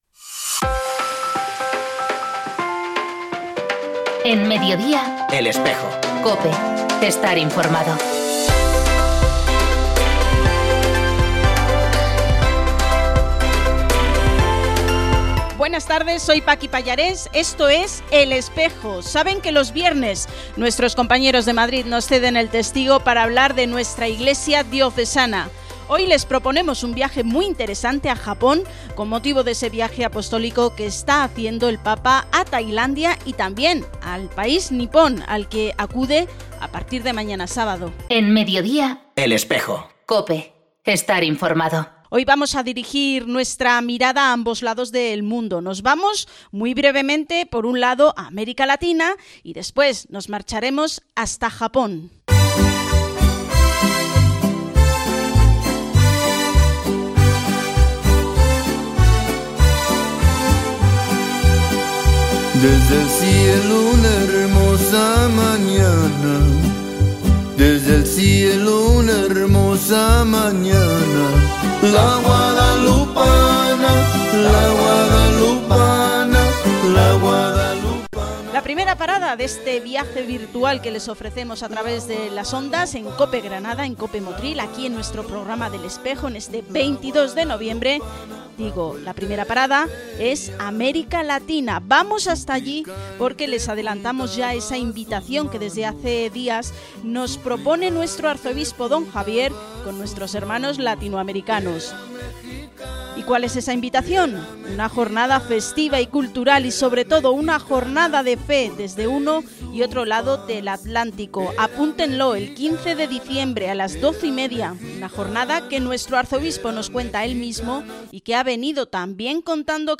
Podcast del programa emitido en COPE Granada (87.6 FM) y COPE Motril (95.2 FM), el 22 de noviembre de 2019, en la víspera de la segunda etapa del viaje apostólico del Santo Padre.